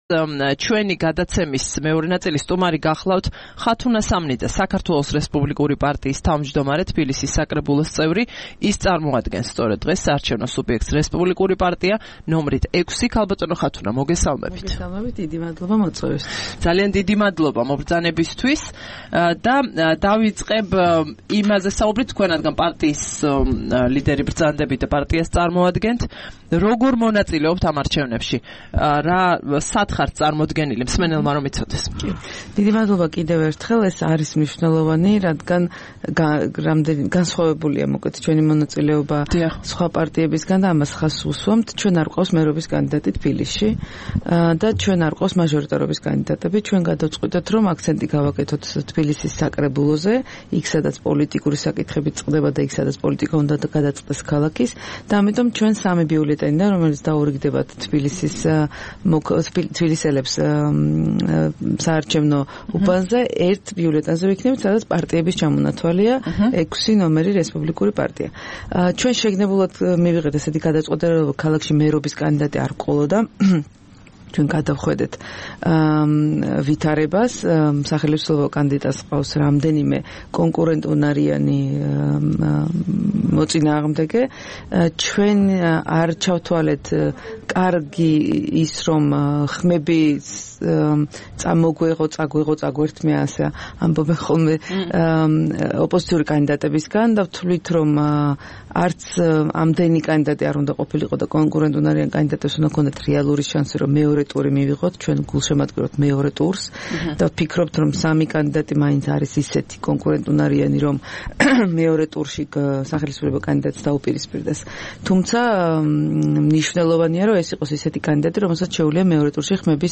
13 ოქტომბერს რადიო თავისუფლების "დილის საუბრების" სტუმარი იყო ხათუნა სამნიძე, საქართველოს რესპუბლიკური პარტიის თავმჯდომარე.